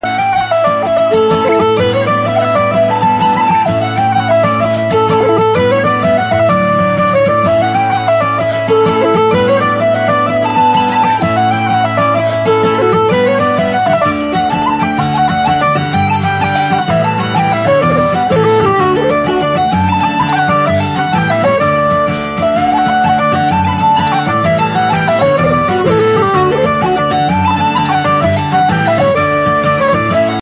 The Ivory Bowl (Single Jigs)